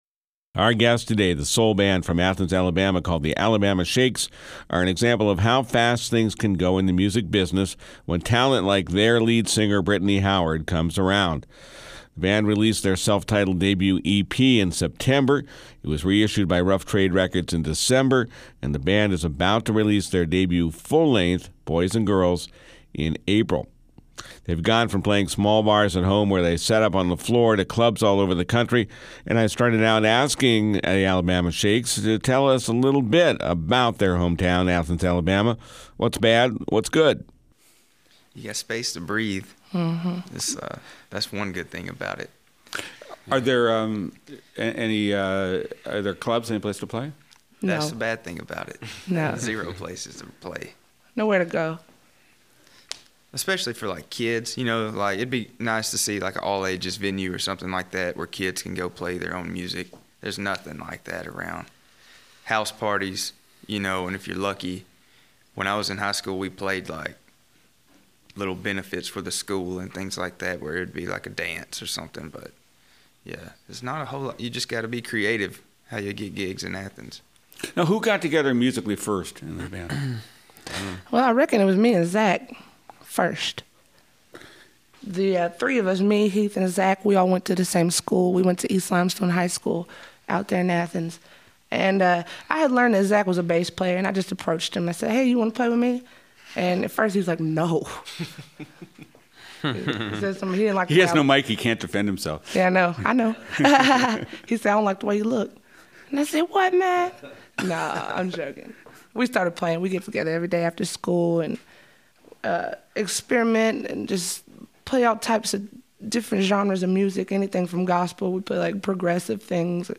infuse rock 'n' roll with soul, blues and country.